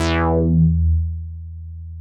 MICROMOOG E3.wav